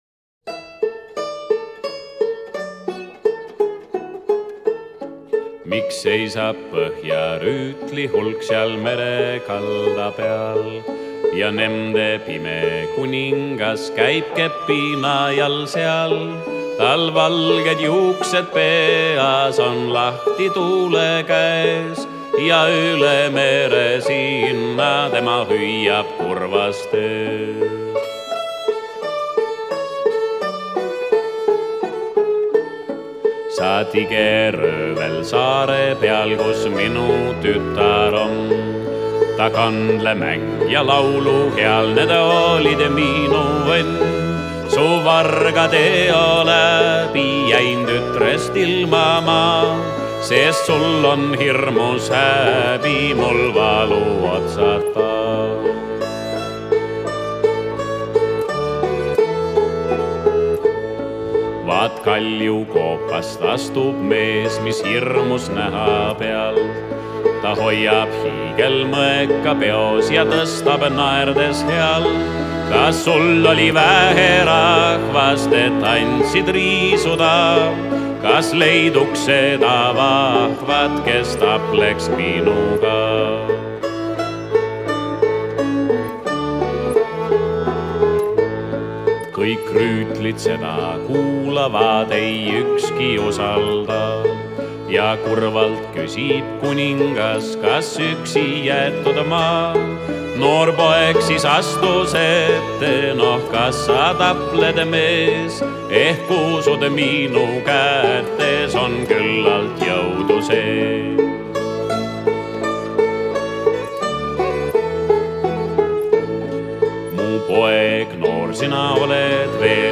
rahvalik